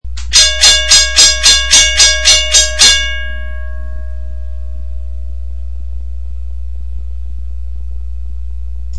The object above is what operates the hammer that rings the gong.
CLICK THE ICON TO THE LEFT TO HEAR THE BELL IN ACTION.